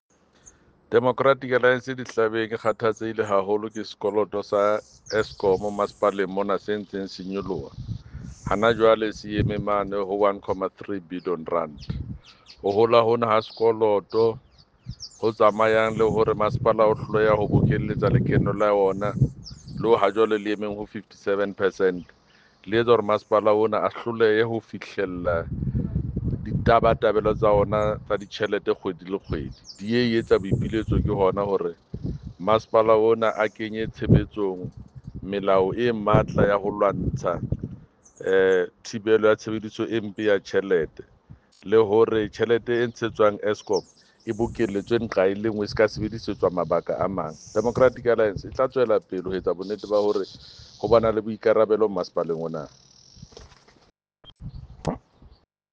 Sesotho soundbite by Jafta Mokoena MPL.